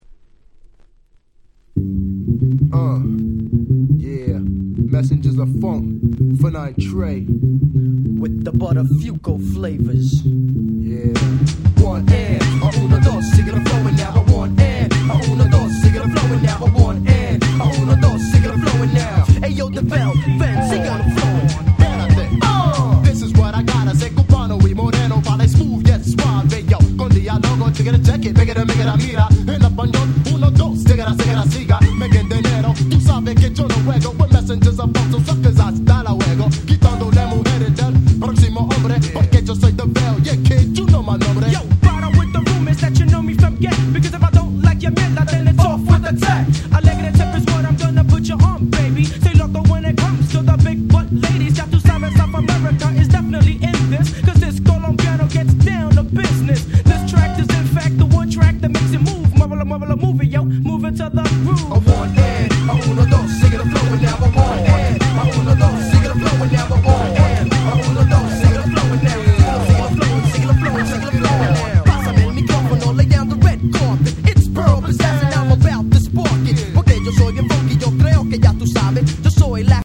93' Nice Hip Hop !!
イケイケNew Schoolチューンです！！
メッセンジャズオブファンク 90's Boom Bap ブーンバップ